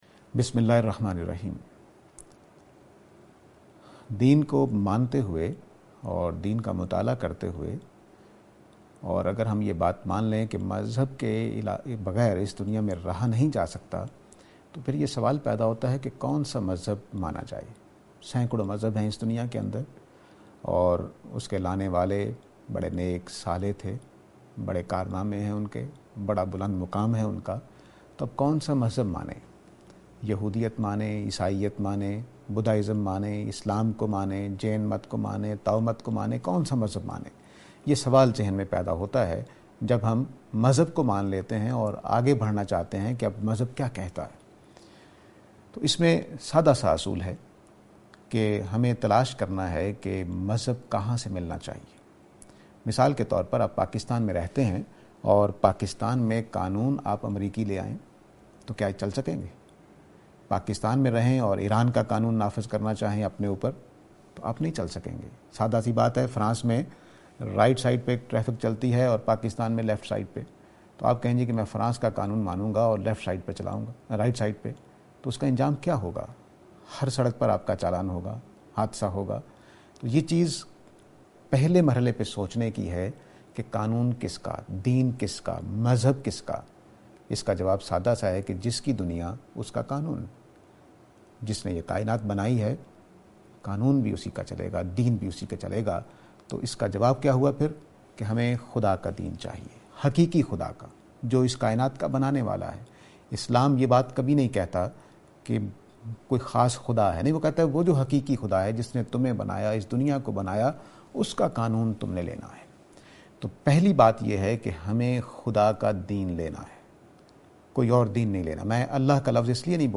This lecture is and attempt to answer the question "Why Islam?".